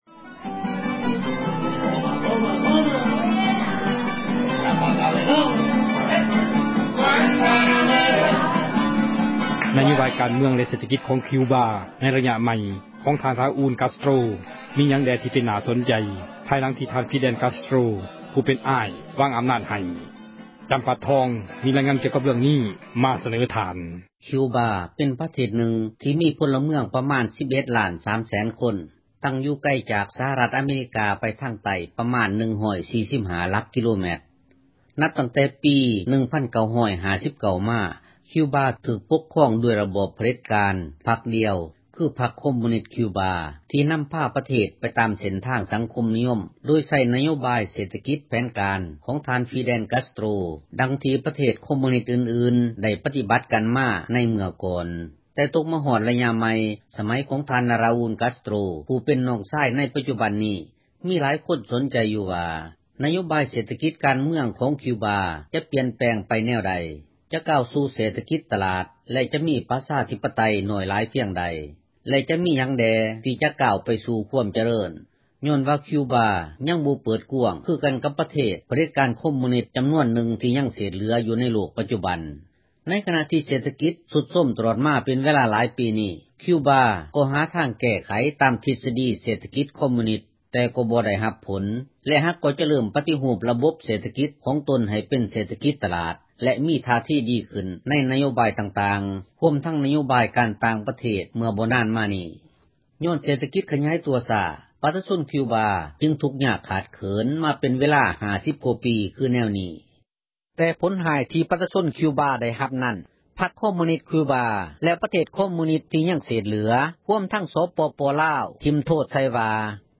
ມີຣາຽງານກ່ຽວ ກັບເຣື້ອງນີ້ ມາສເນີທ່ານ.